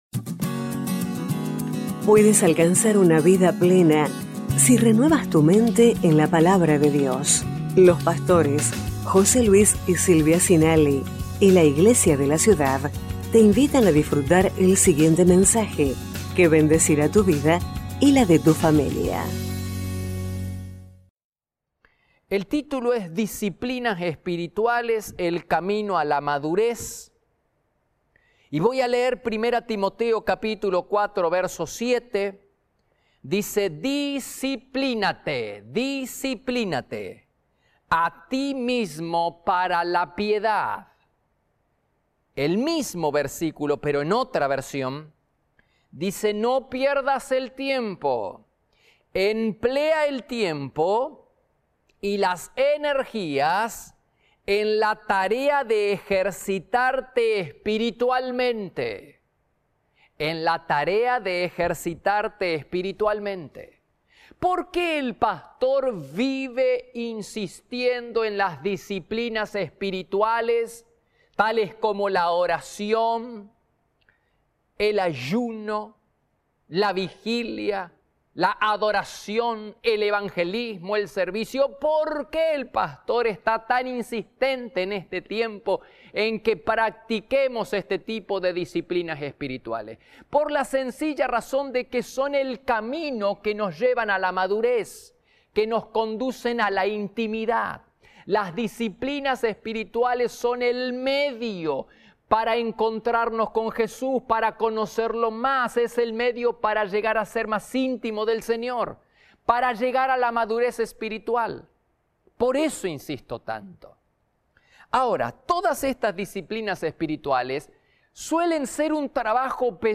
Iglesia de la Ciudad - Mensajes / Disciplinas espirituales: camino a la madurez - 26/05/19 (#1041)